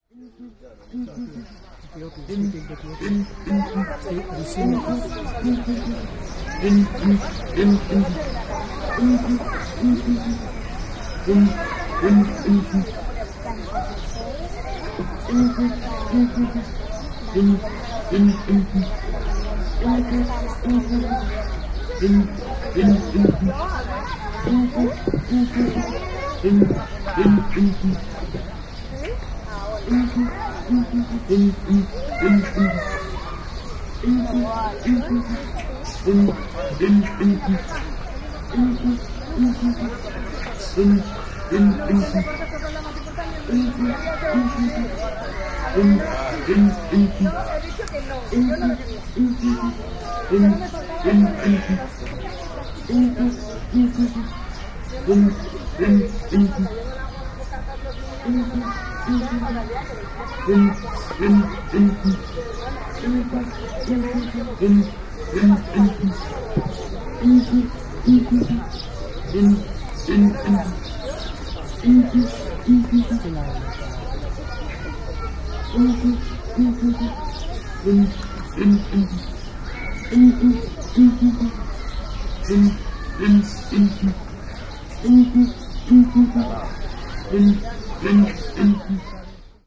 描述：一只雄性珍珠鸡（Pavo real，学名：Pavo cristatus）的叫声，和一只南方尖叫鸟（Chajá，学名：Chauna torquata）的背景叫声，以及动物园的环境音。
标签： 西班牙 孔雀 南方 尖叫 巴塞罗那 ZooSonor 现场记录 动物
声道立体声